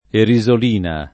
erisolina [ eri @ ol & na ]